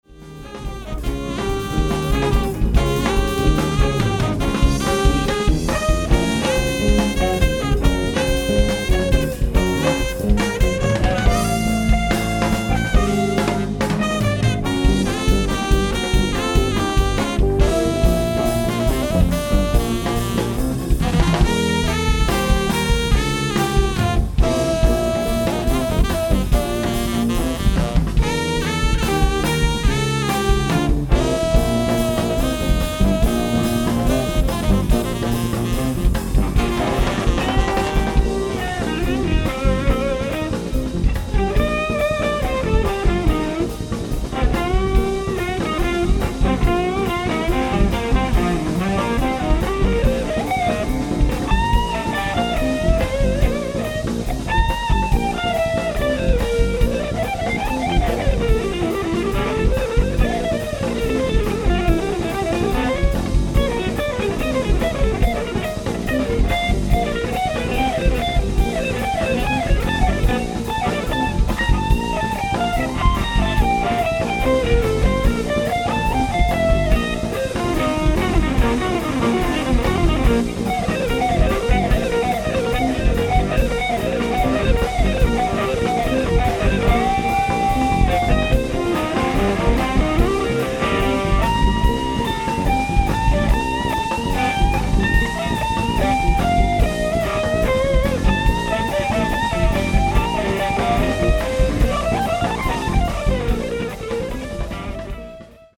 ライブ・アット・ボギー＆ベス、ウィーン、オーストリア 11/02/2025
ステレオ・サウンドボード収録！！
※試聴用に実際より音質を落としています。